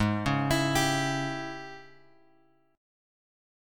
G#M7sus4#5 chord {4 4 x x 5 3} chord